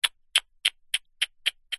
Звуки человека
Человек причмокивает языком